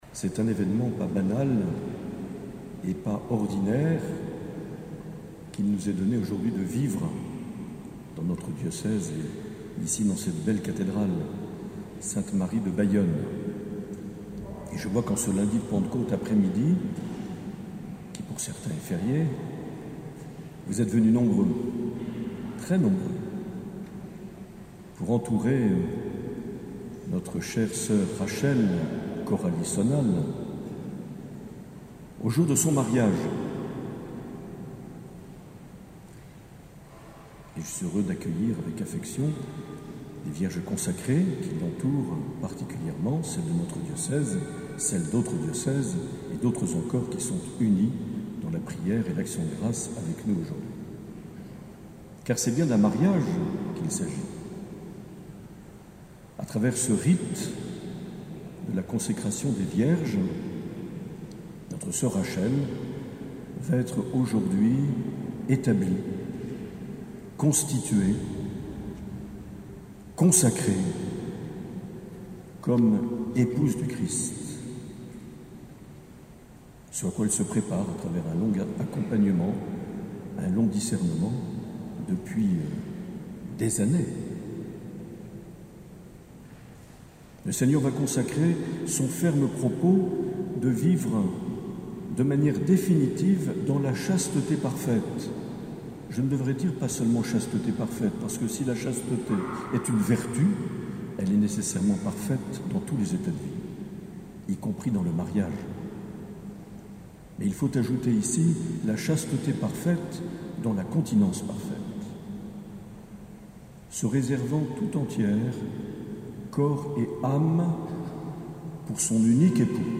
Cathédrale de Bayonne
Accueil \ Emissions \ Vie de l’Eglise \ Evêque \ Les Homélies \ 10 juin 2019
Une émission présentée par Monseigneur Marc Aillet